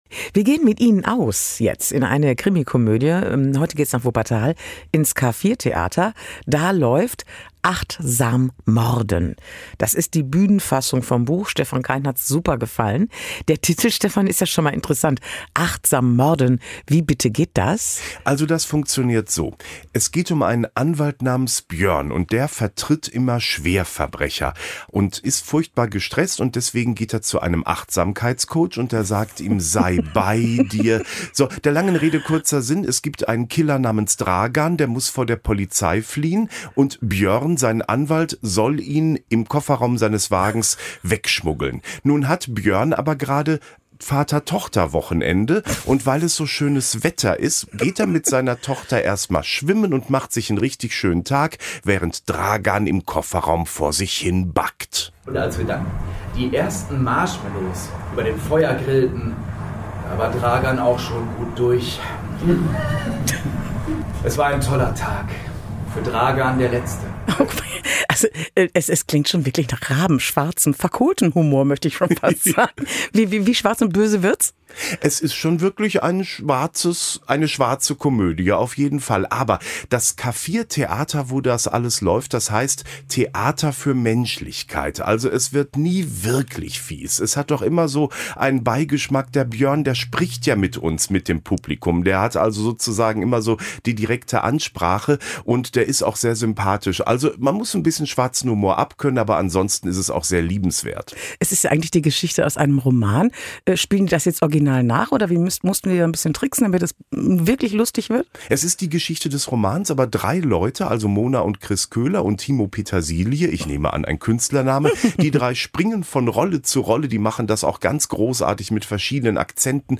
Kulturtipp "Achtsam morden" im K4